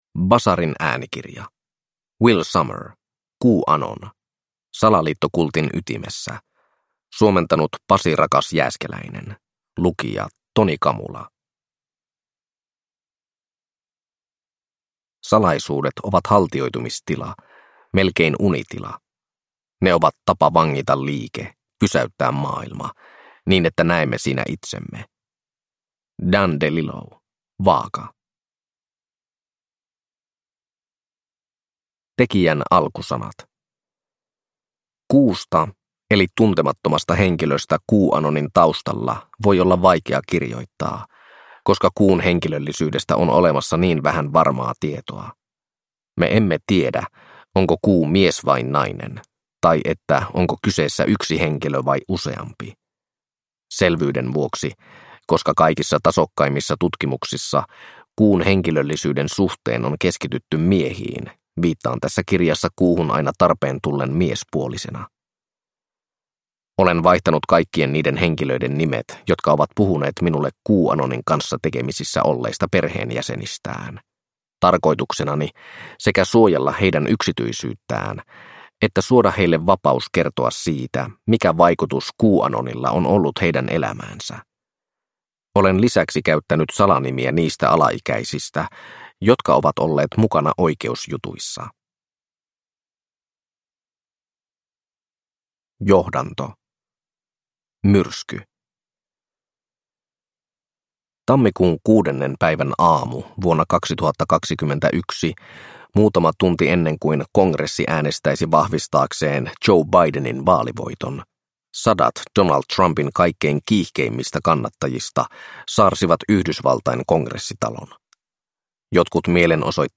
QAnon – Ljudbok – Laddas ner